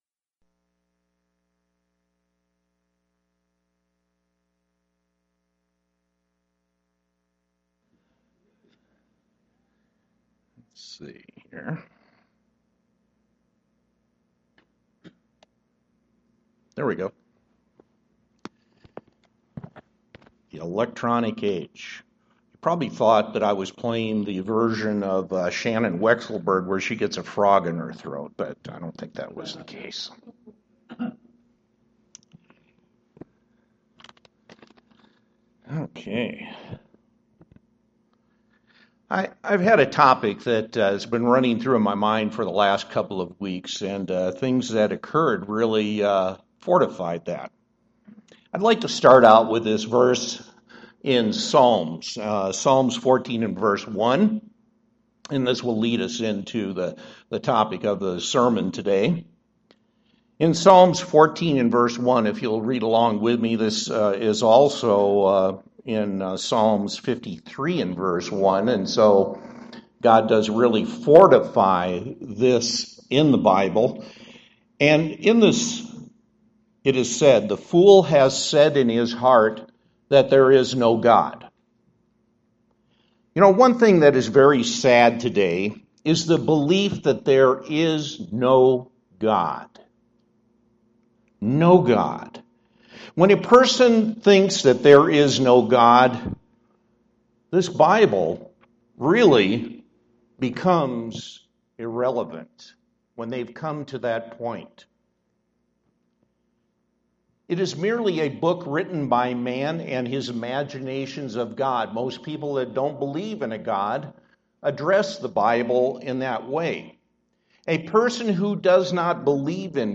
UCG Sermon Studying the bible?
Given in Denver, CO